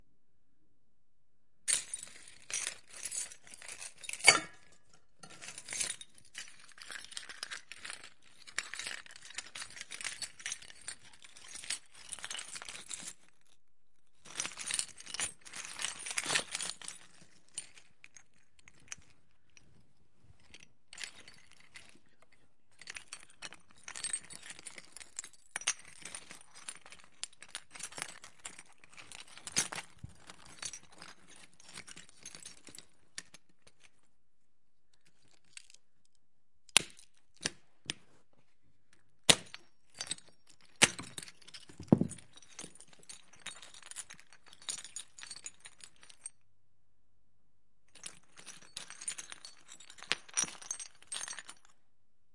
家用机器" 玻璃碎片
描述：玻璃碎片的碎片
Tag: 碎片 玻璃